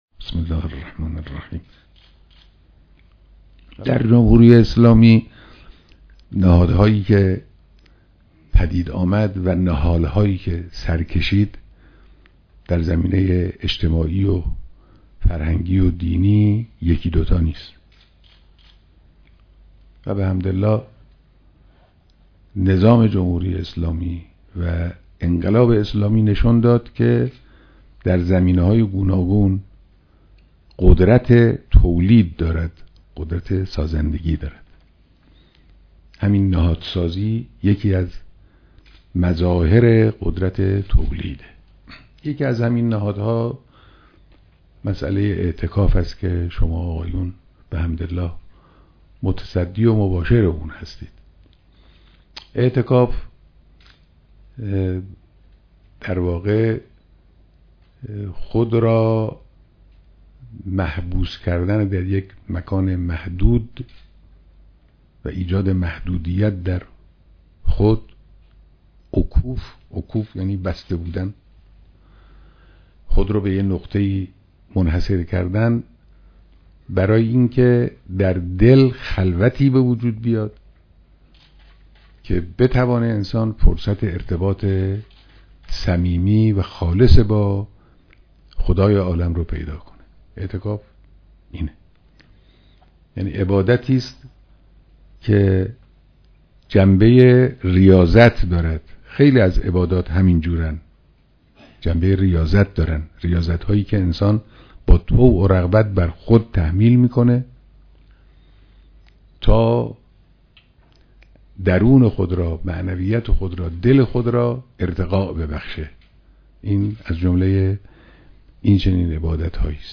بیانات در دیدار اعضای ستاد مرکزی و جشنواره اعتکاف